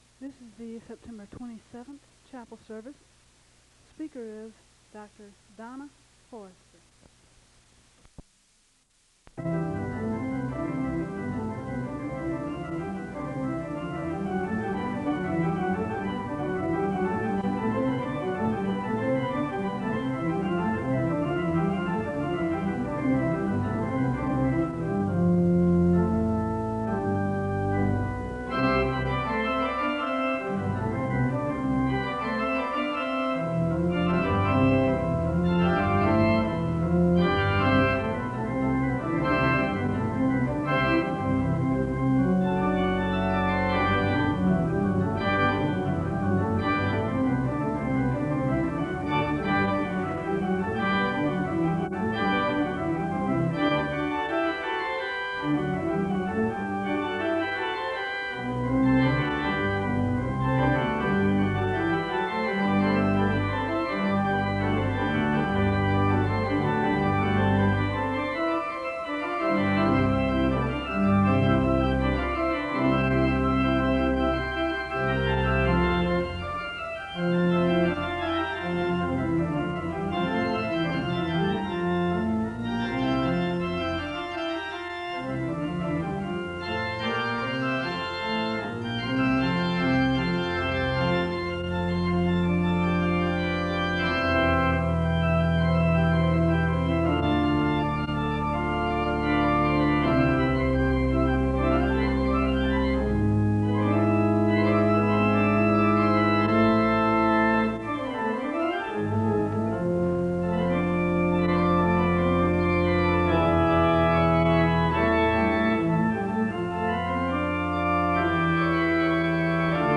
The service begins with organ music (00:00-04:47). The speaker gives a word of prayer (04:48-06:12).
The choir sings the anthem (08:43-13:27).
The service ends with a benediction (33:57-34:10).